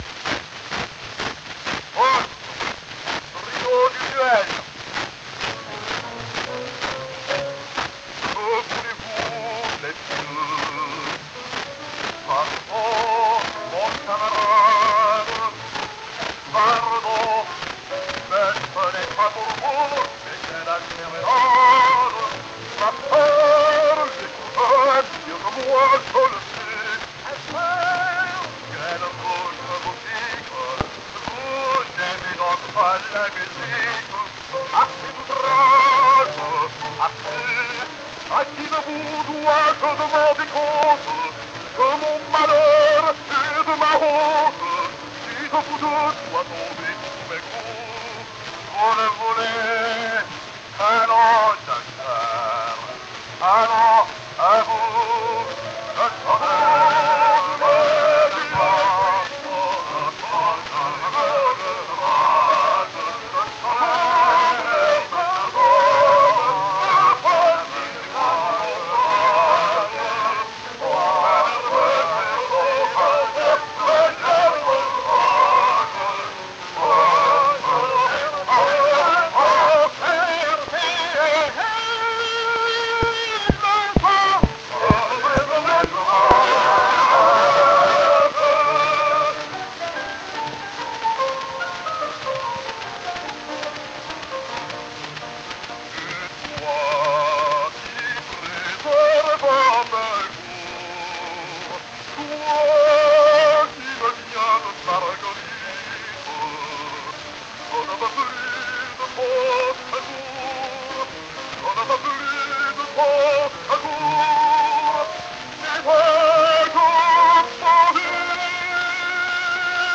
Trio du Duel. Gesangstrio mit Klavierbegleitung.
Die Aufnahme des Gesangs von drei stimmgewaltigen Opernsängern mit den begrenzten Möglichkeiten der akustisch-mechanischen Phonographentechnik war um 1900 ein gewagtes Unterfangen mit wenig überzeugendem Ergebnis.